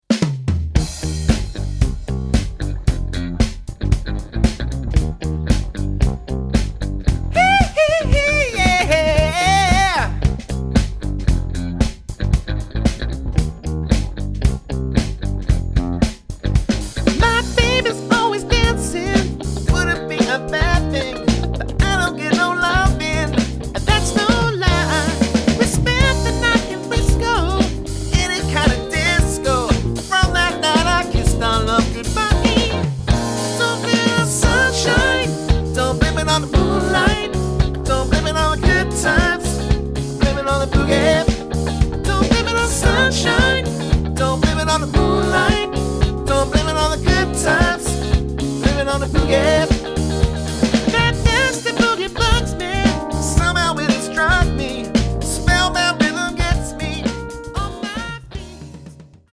bursting with vibrance, fun and energy
rich, roaring voice
guitar
keyboard
bass
drums
• Highly experienced 4-7 piece function band